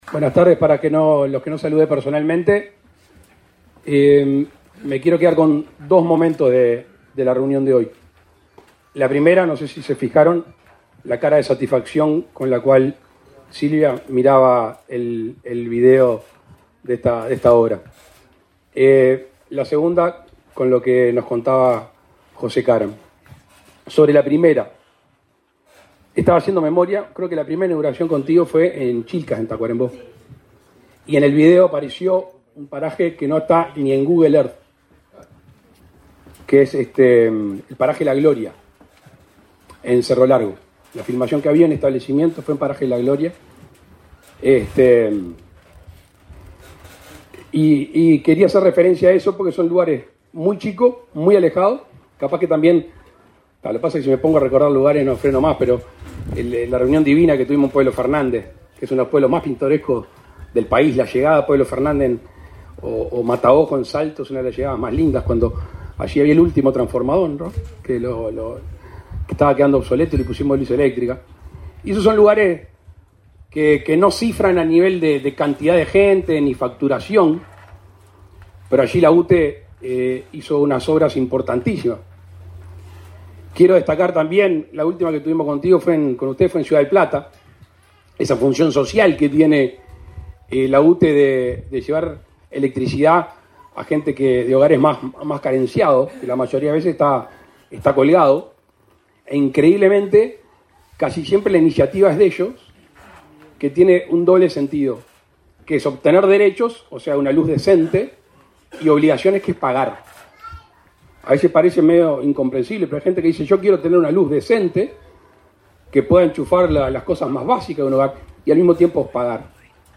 Palabras del presidente de la República, Luis Lacalle Pou, en inauguración de obras de UTE